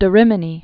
(də rĭmə-nē, dä rēmē-nē) Died c. 1285.